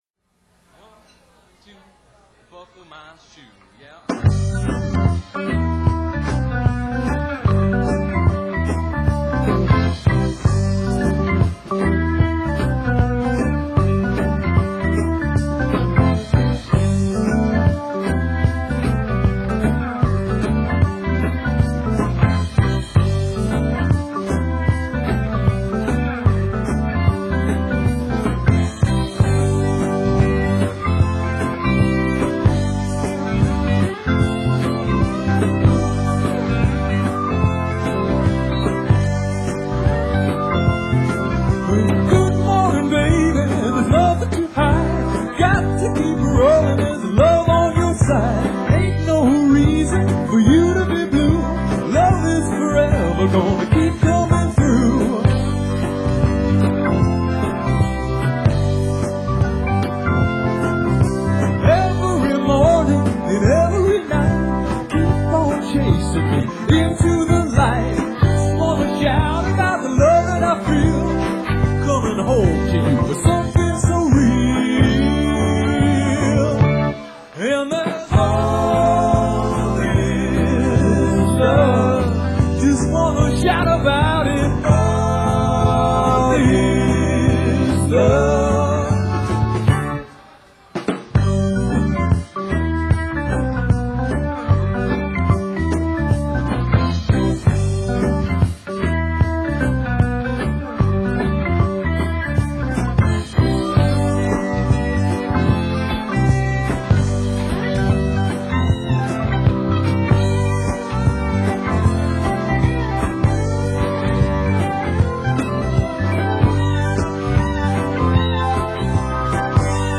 drums
guitar & vocals
bass & vocals